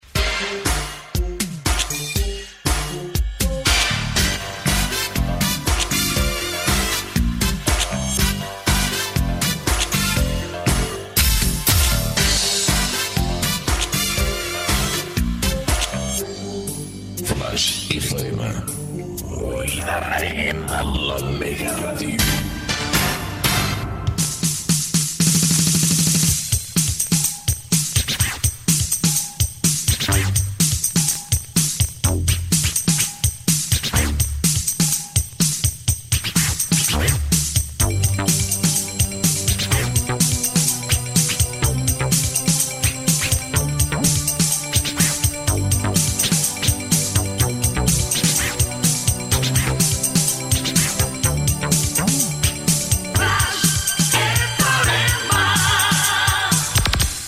Dos indicatius de l'emissora
Primers indicatius de l'emissora.